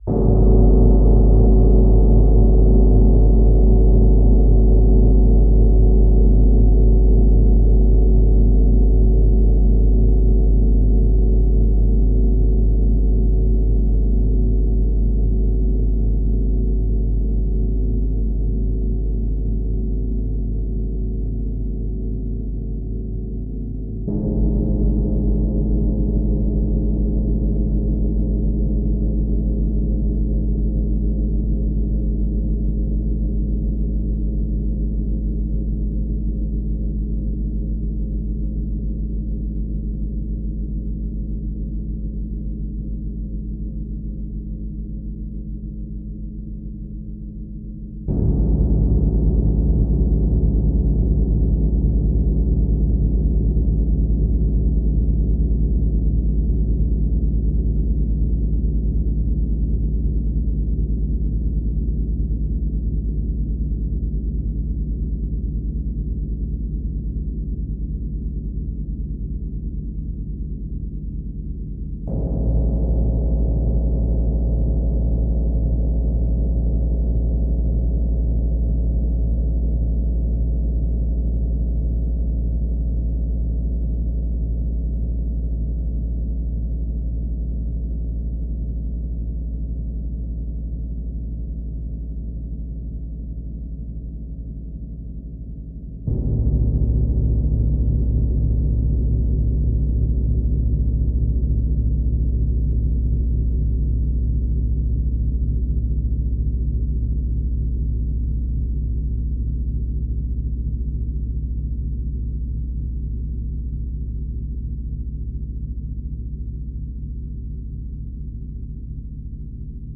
60 inch Tam-tam